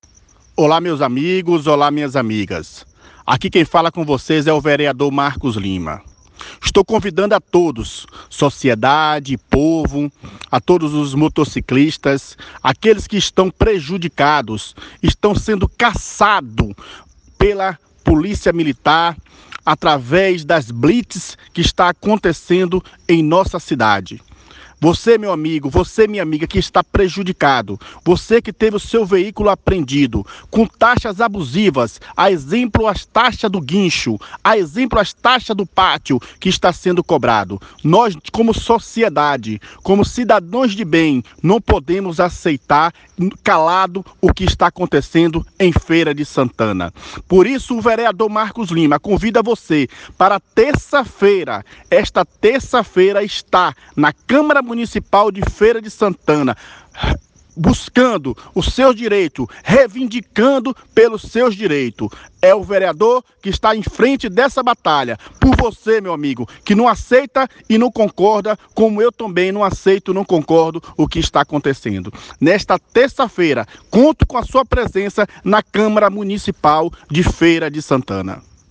Rota da Informação - Vereador Marcos Lima faz apelo dramático e convoca a população para ir a Câmara Municipal contra as prisões de motos na cidade